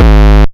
Index of /m8-backup/M8/Samples/breaks/breakcore/earthquake kicks 1
shallow kick 2.wav